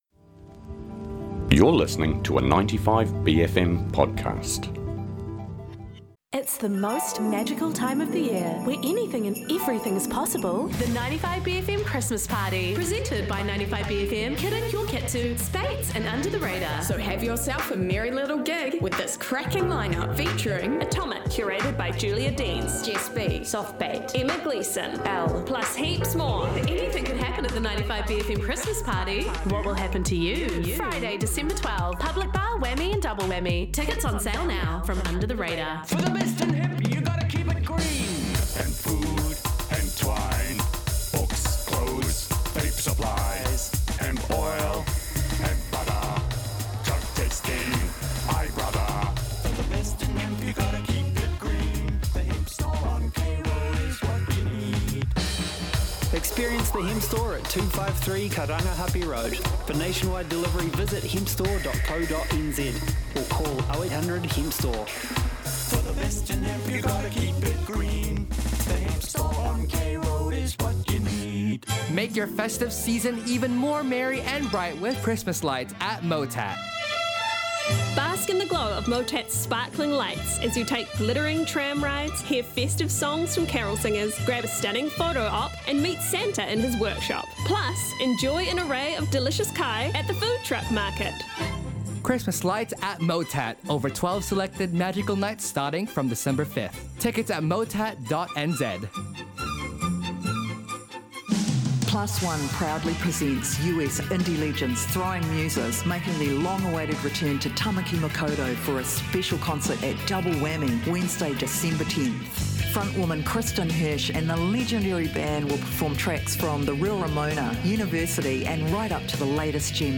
Black metal Bohdran, Modern medical machines of Scottish Hospitals & ancient wax cylinder home recordings including someone whistling for a dog while someone else plays a trumpet recorded in the late 1890's! Recordings from disability support service music programs from London, Wellington, Adelaide, & Melbourne. Old NZ songs about Germs & a disinfectent company promotional jazz flexidisc. Blind guitar evangelists, grindcore sung by pitbulls & a crooning undertaker who survived a plane crash & 50 skingraft surgeries.
Communities, ceremonies & field recordings.